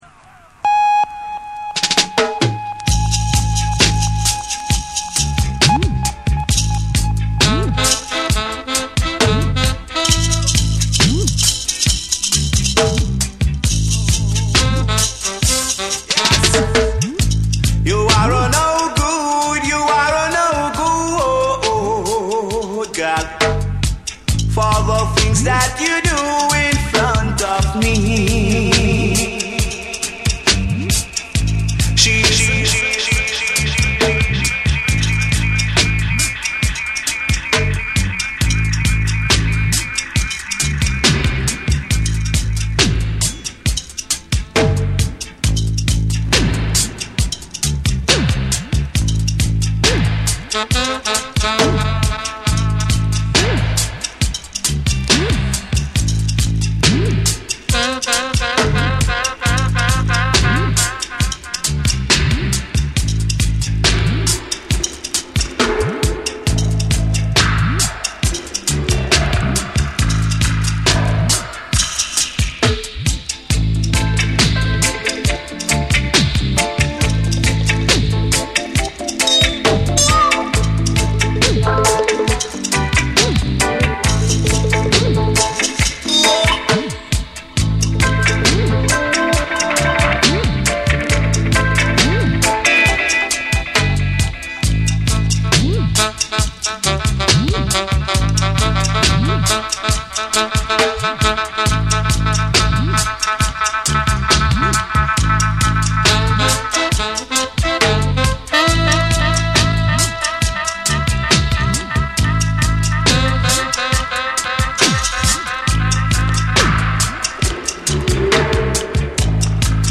エコーとリバーブが縦横無尽に飛び交い、闇とユーモアが同居する音響世界を展開する。
REGGAE & DUB